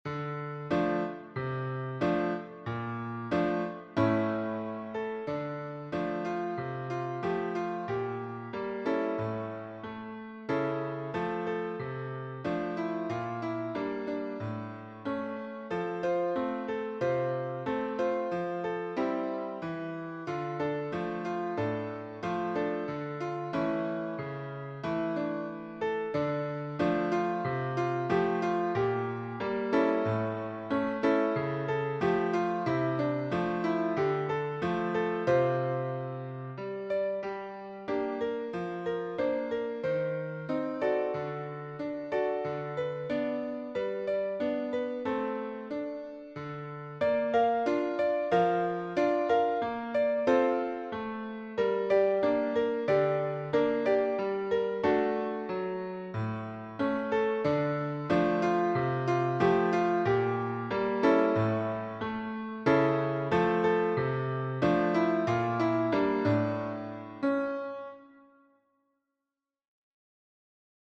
Joyous
Ragtime cover
Piano and voice